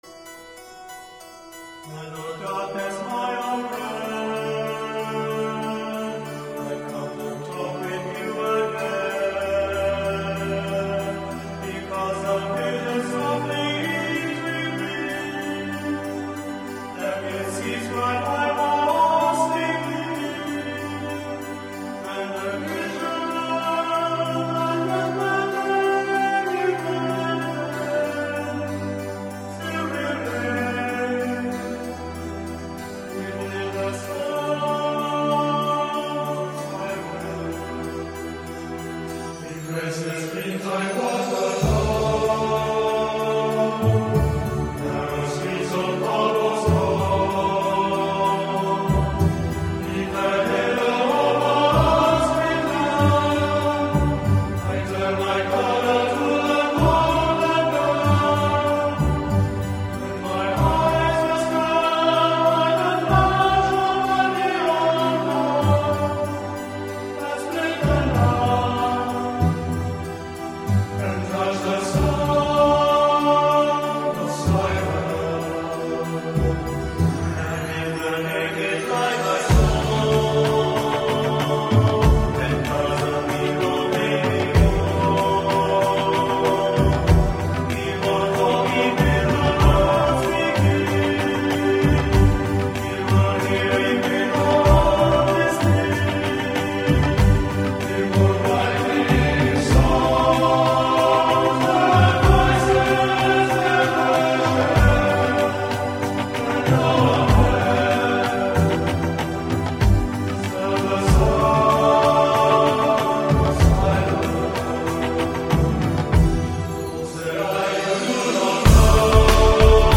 آهنگ راک